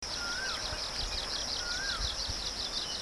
Songs
7 May 2012 Tin Shui Wai
This was thought to be a different individual from the previous one, although it was quite close and the song was similar, ie most probably borealis.